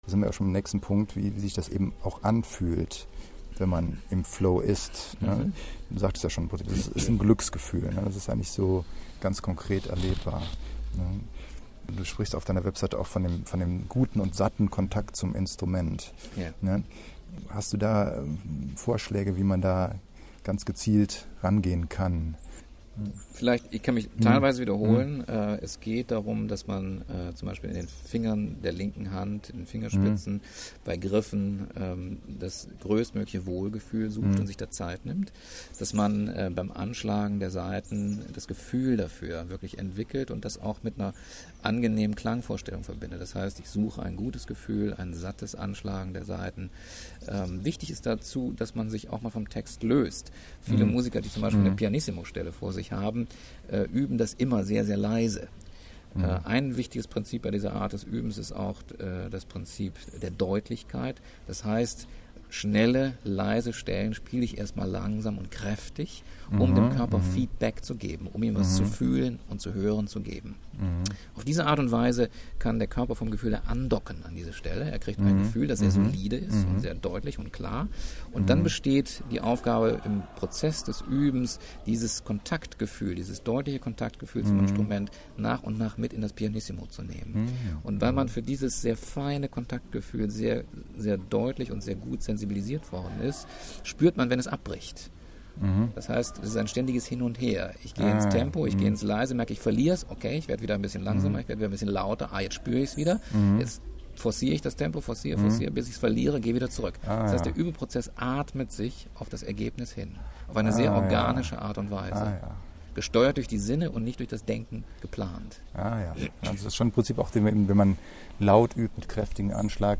Bericht vom Seminar & Audio Interview
Interview